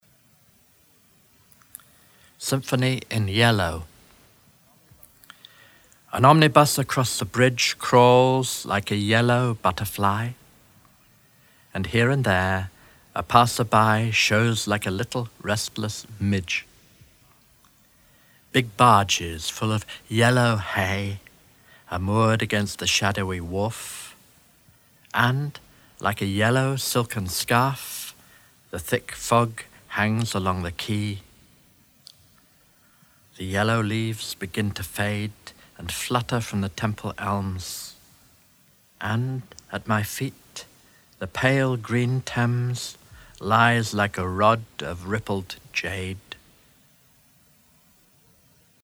Recordings from a selection of 72 Poems on the Underground originally recorded on tape and published as a Cassell Audiobook in 1994
Symphony in Yellow by Oscar Wilde read by Adrian Mitchell